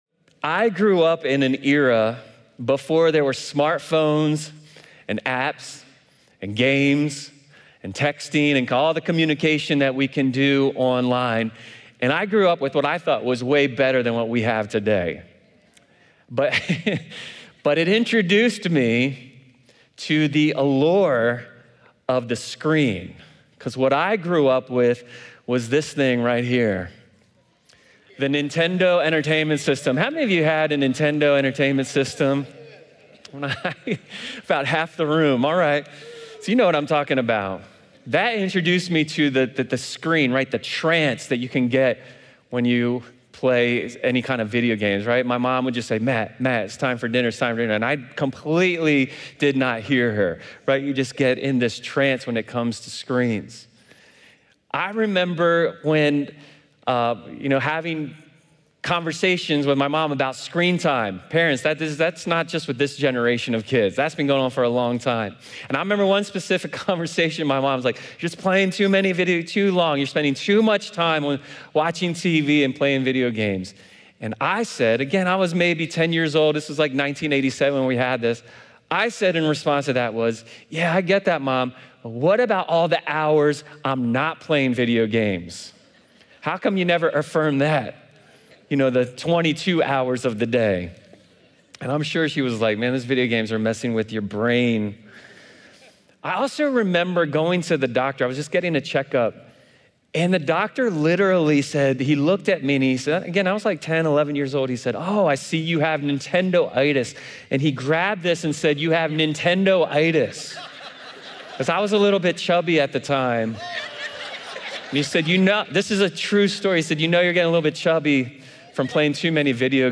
This sermon explores how screens become idols when their influence shapes our beliefs more than Christ.